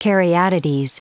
Prononciation du mot caryatides en anglais (fichier audio)
Prononciation du mot : caryatides